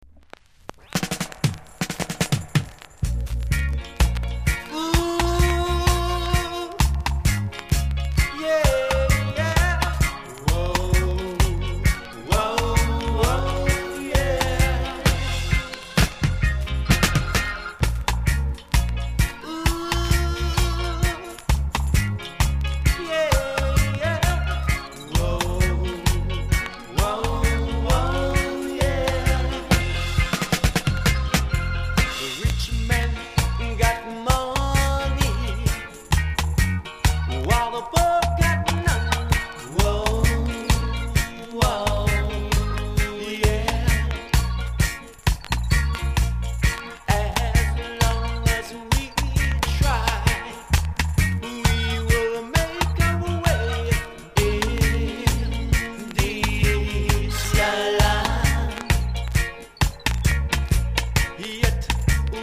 ※チリ、パチノイズが単発で所々あります。